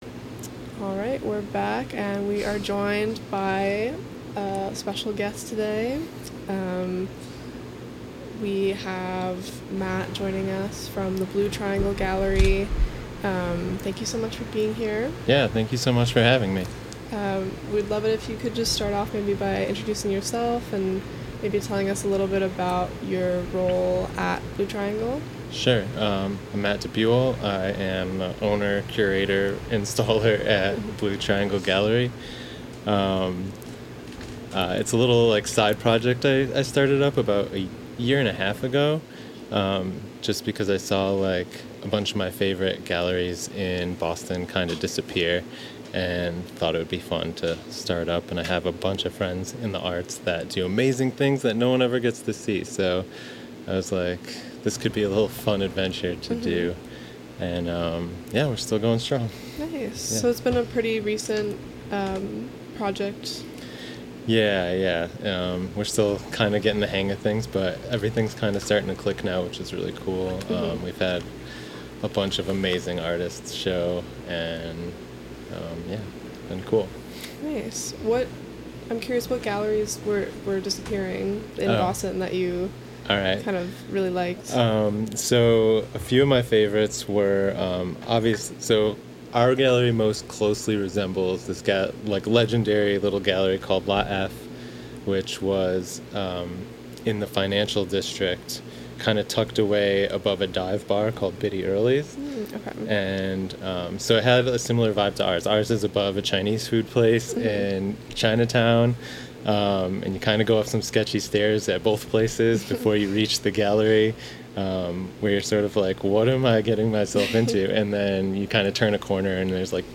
Interview with Blue Triangle Gallery